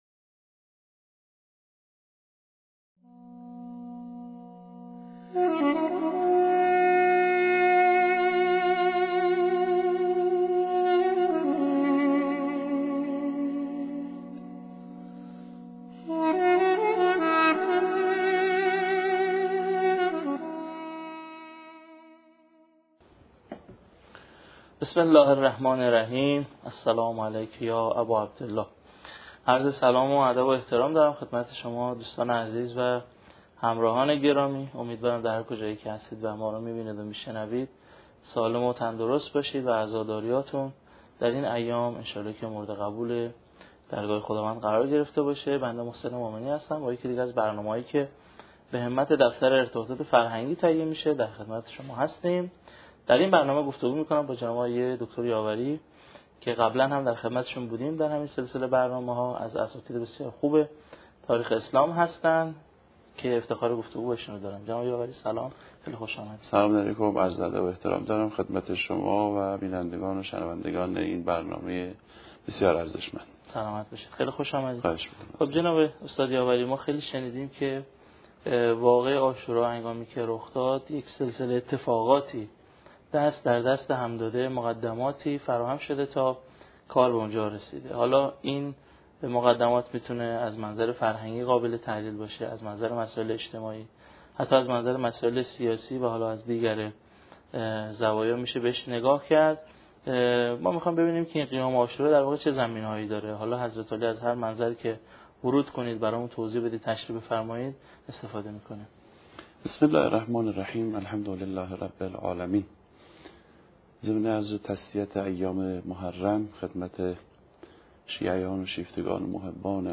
این گفت‌وگو به همت دفتر ارتباطات فرهنگی تهیه شده و در شبکه‌های اجتماعی و سایت این دفتر منتشر می‌شود. 2.